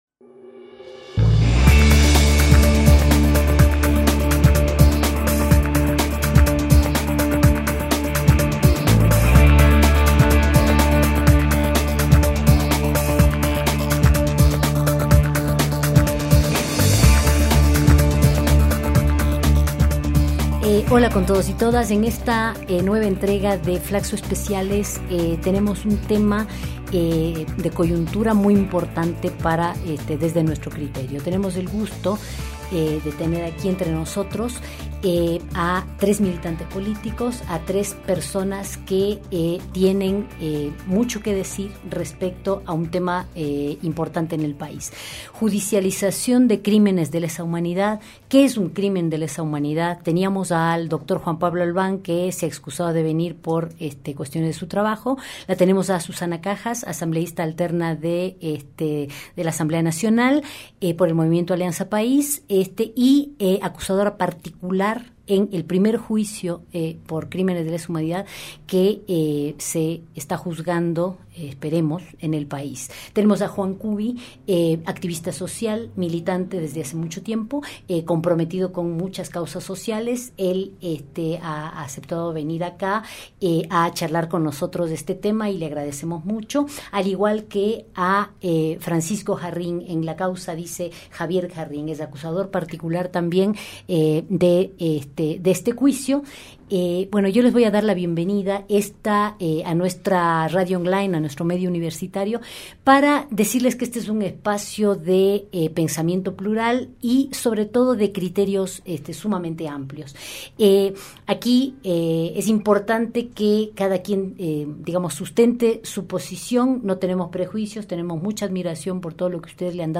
En Ecuador se ha comenzado a vivir un tema sin duda inédito: el primer juicio de crímenes de lesa humanidad. Para este conversatorio participaron